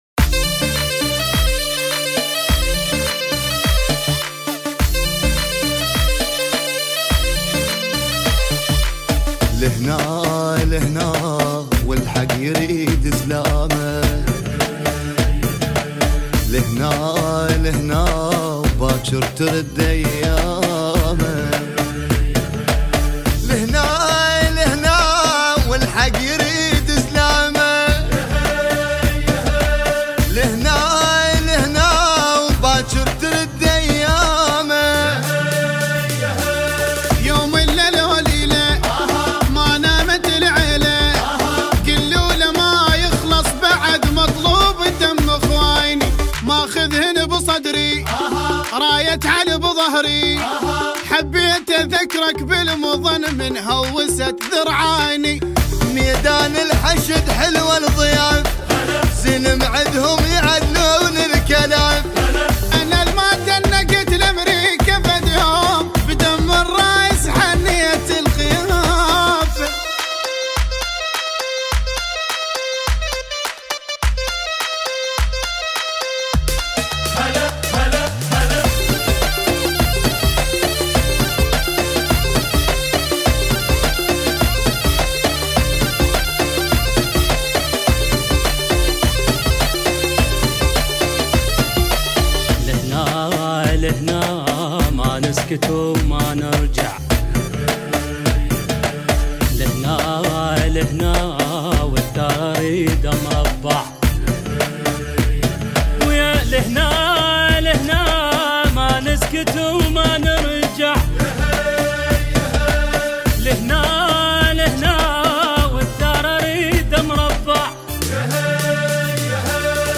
افتراضي اوبريت _ هوسة عراقية لهنا لهنا- حصريآ 2020